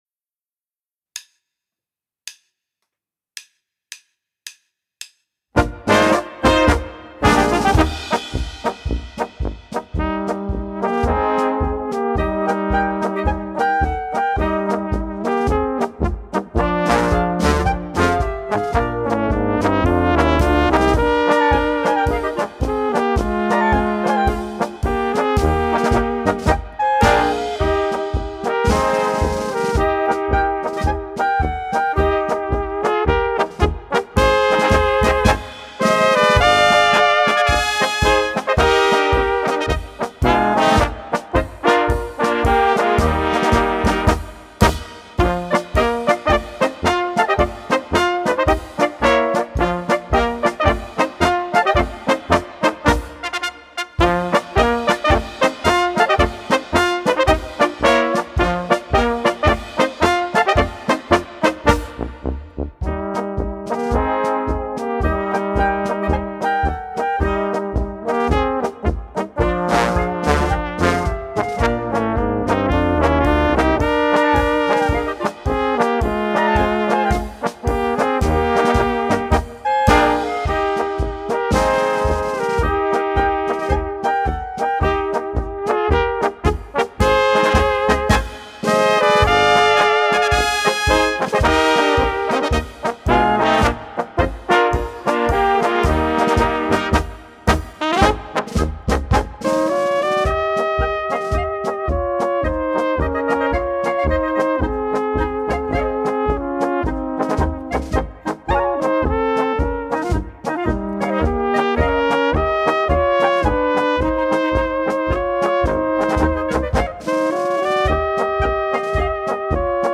Besetzung: Trp
Untertitel: 21 Blasmusik-Hits mit Playalongs